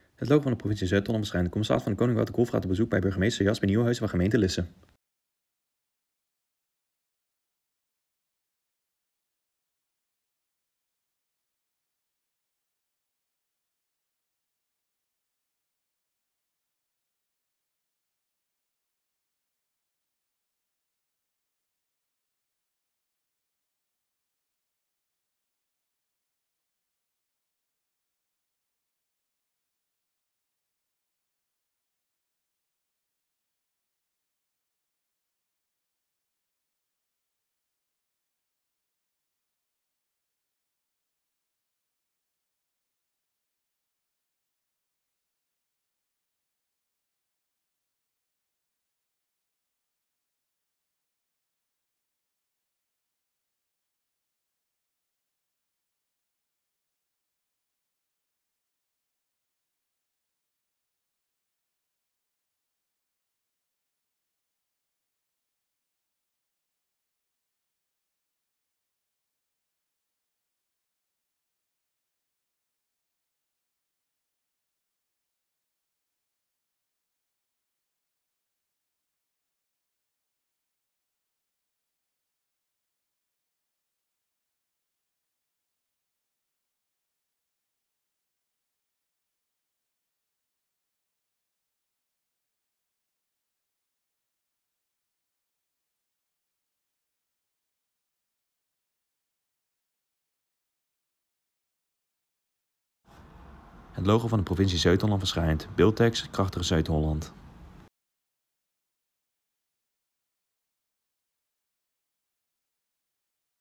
CdK in gesprek met burgemeester Lisse